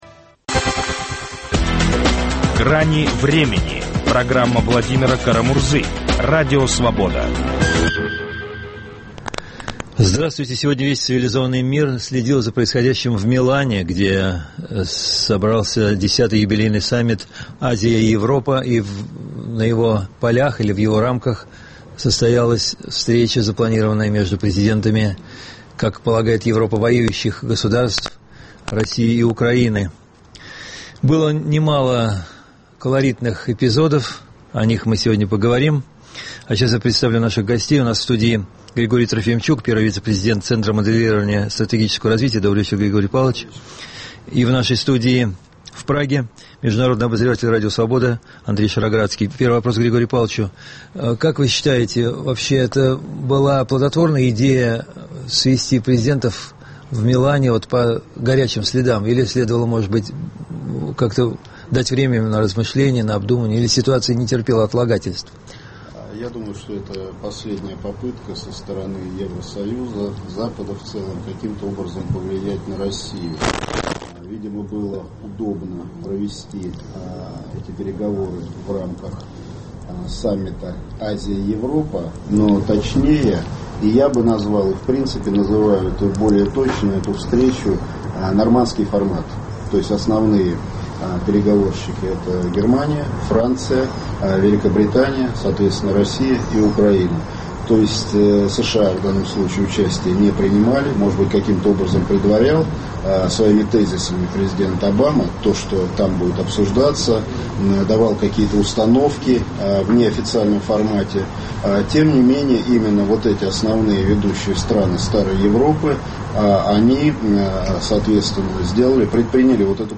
Состоится ли "дорожная карта" украинского урегулирования? Обсуждают - политологи